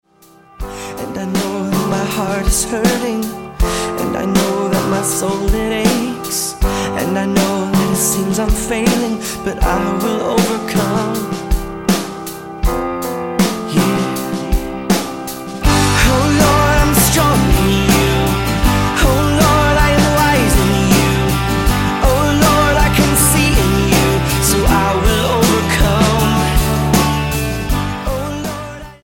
STYLE: Pop
slow tempo praise songs